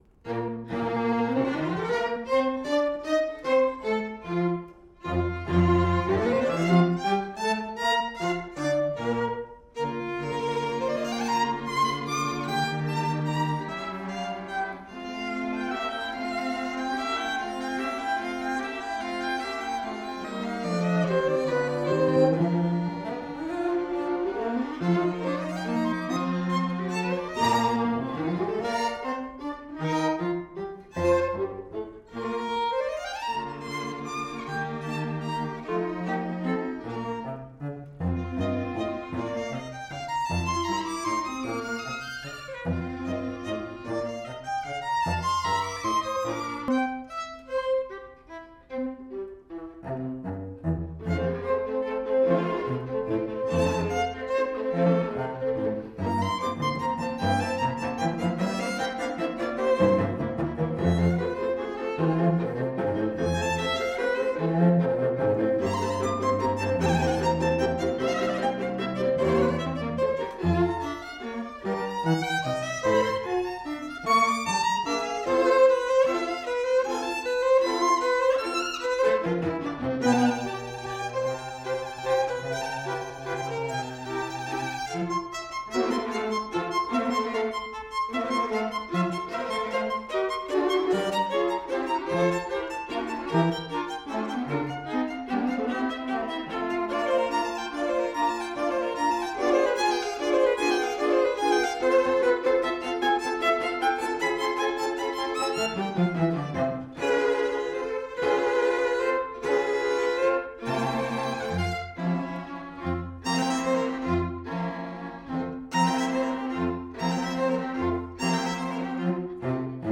Soundbite 1st Movt
The music is lively and upbeat.